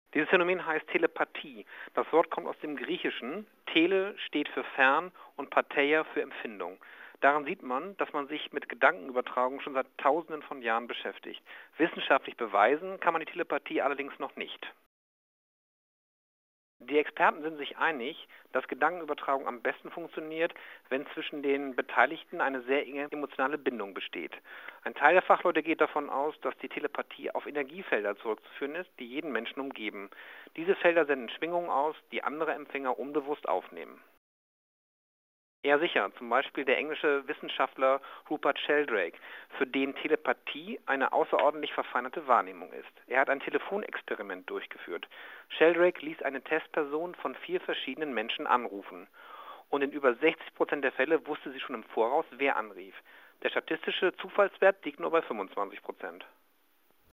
Kurzinterview Kurzinterview